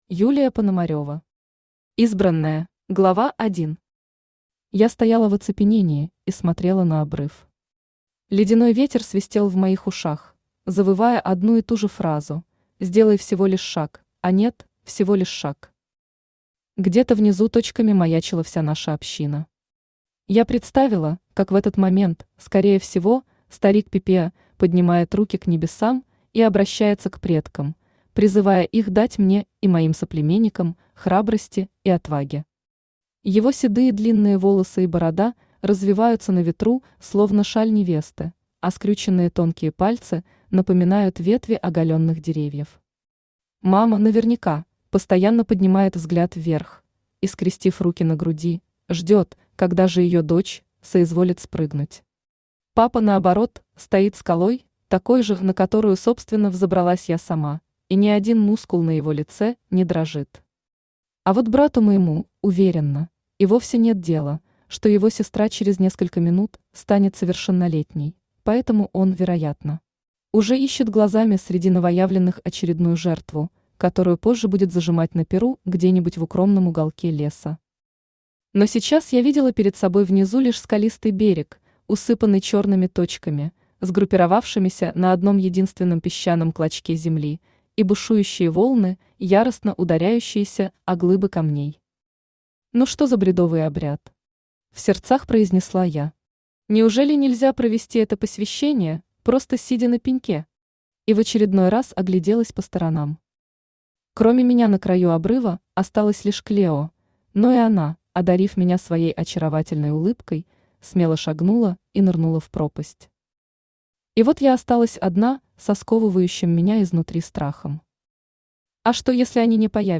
Аудиокнига Избранная | Библиотека аудиокниг
Aудиокнига Избранная Автор Юлия Сергеевна Пономарева Читает аудиокнигу Авточтец ЛитРес.